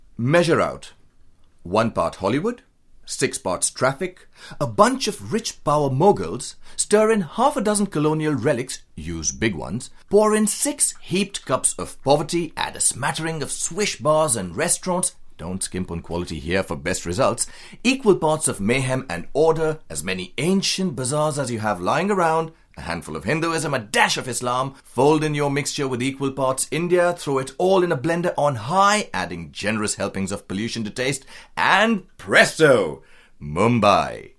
Hindi, Male, Home Studio, 30s-40s